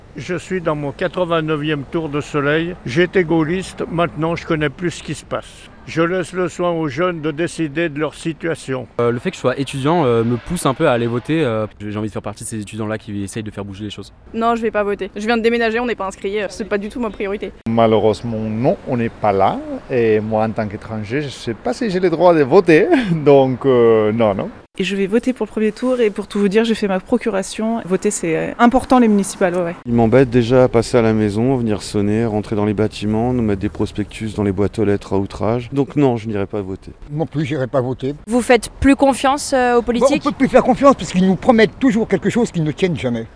La Rédaction est allée vous poser la question dans la Vallée de l'Arve.